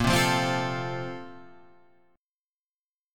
A#M7sus2 chord